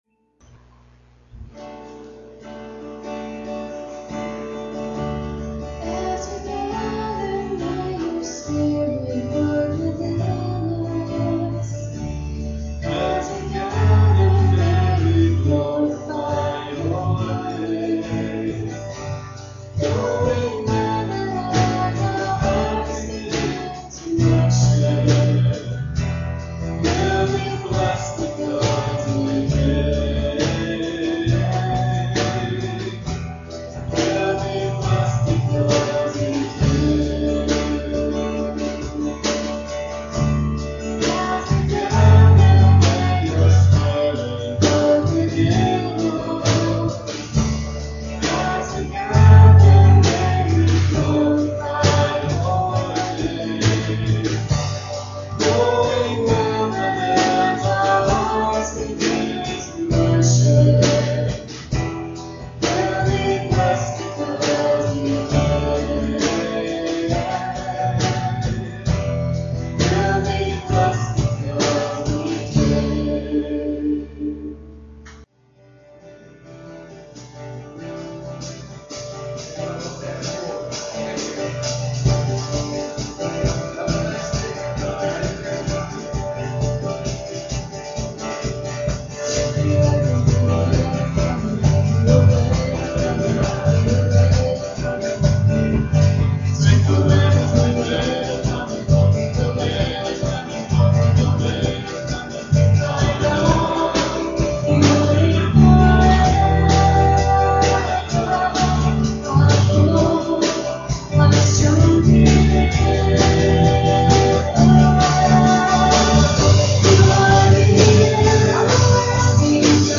PLAY God's Volunteers: (6) Paul, September 11, 2011 Scripture: Acts 9:1-6. Message
at Ewa Beach Baptist Church